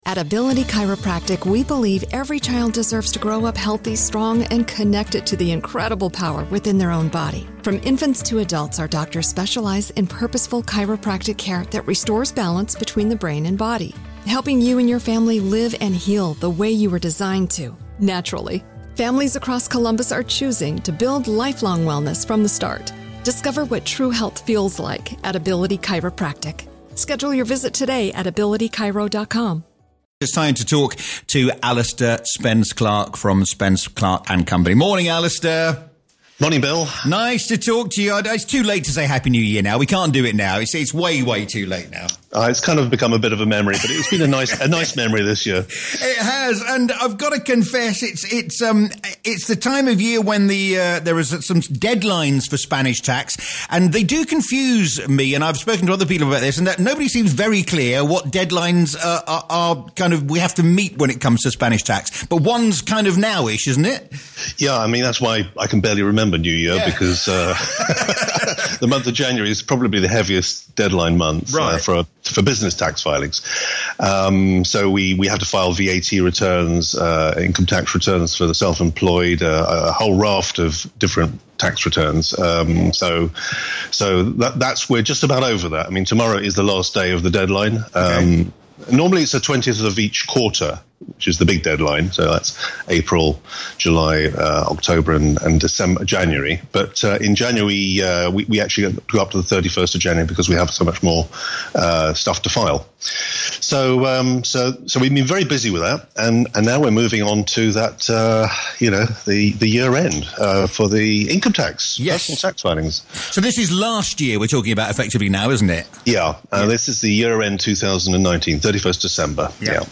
Regular interviews